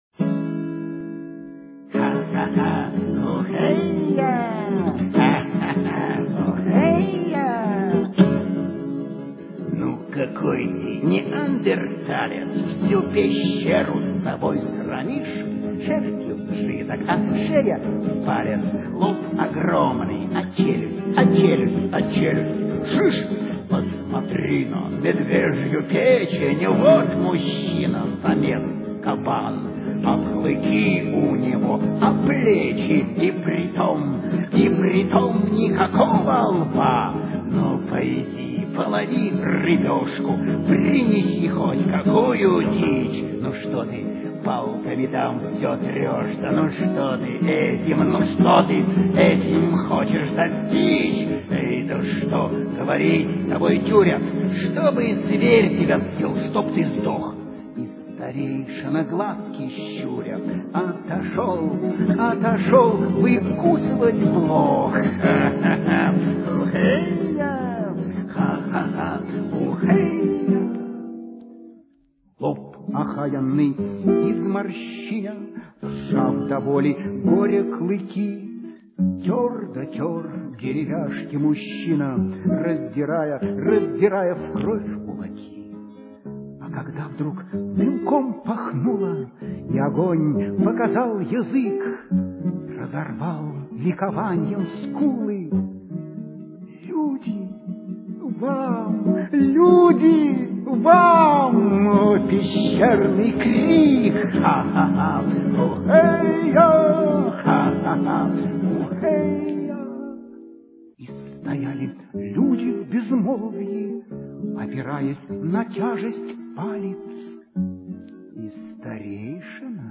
Голоса персонажей: Старейшина, Прометей, Автор, Шаман.